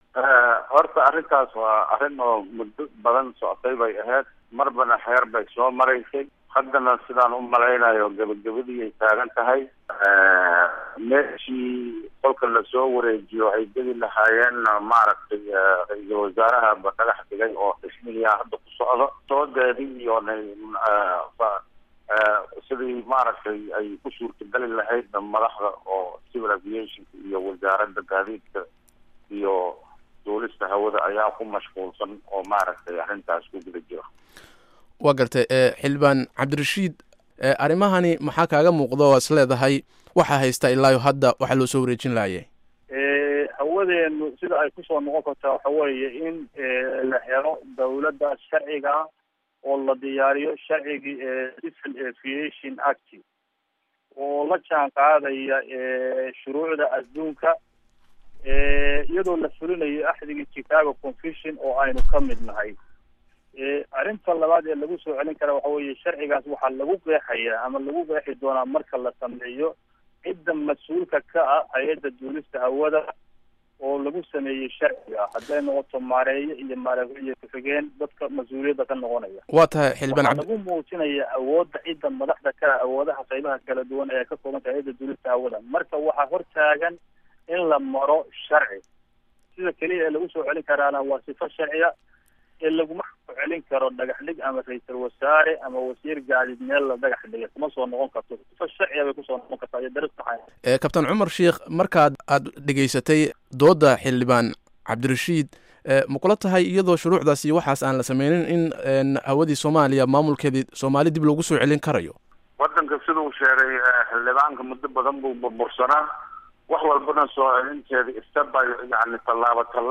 Dood: Maamulka Hawada